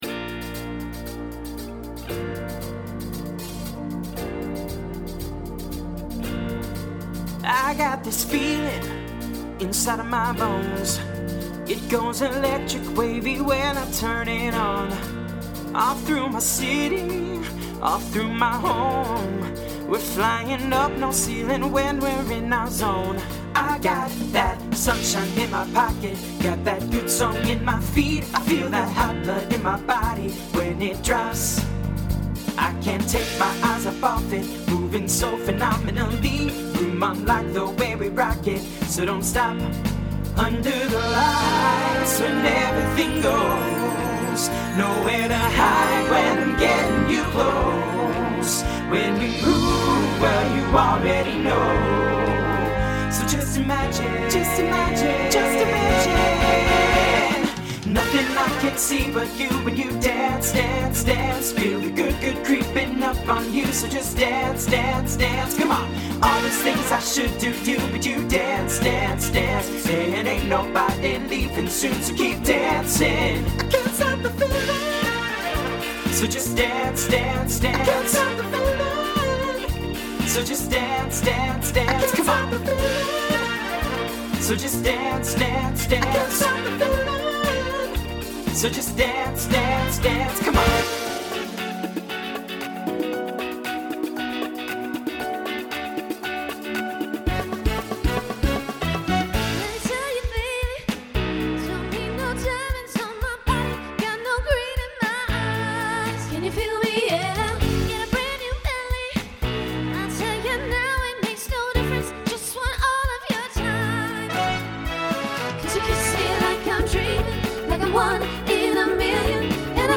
TTB/SSA
Voicing Mixed Instrumental combo Genre Pop/Dance